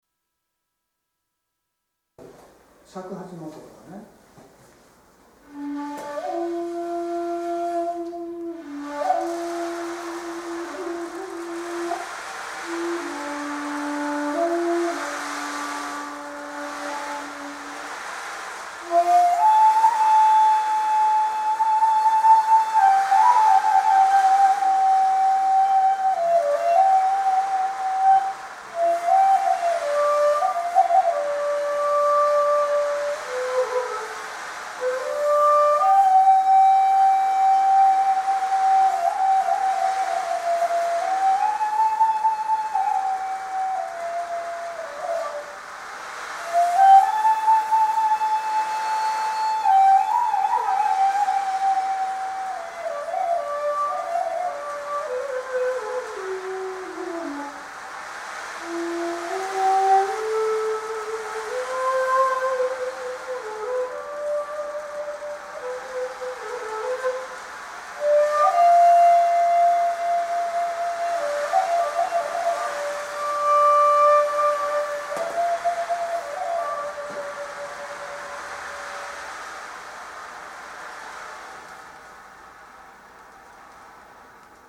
節を残した素朴な竹の音色がほしかったからです。
打ち寄せる波の情景をより分かりやすくするために効果音として民族楽器を使いました。
その音にのってのんびりした自由リズムの追分メロディーが歌っていきます。波の打ち寄せる音と尺八の音色を楽しんでもらえたようでした。